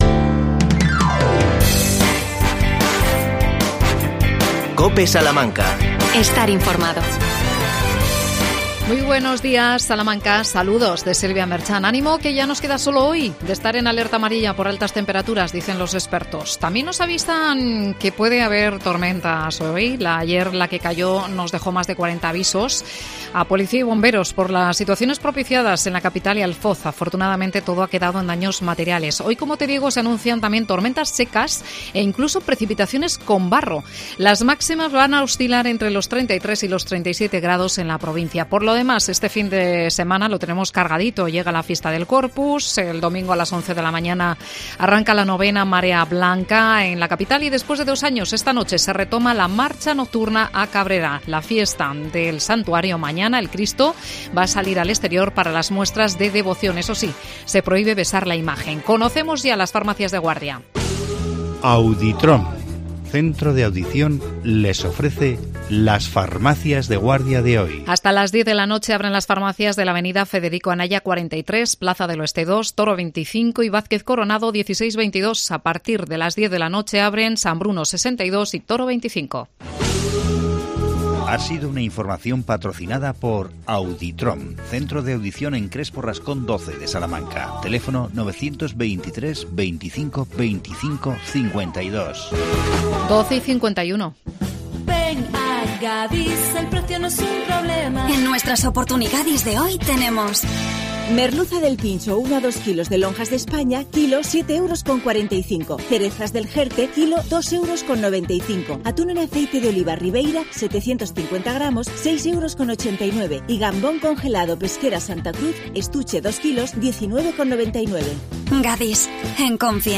AUDIO: Entrevista a la concejala de Medio Ambiente Miriam Rodríguez. Los temas: parques infantiles y premio ARBOL.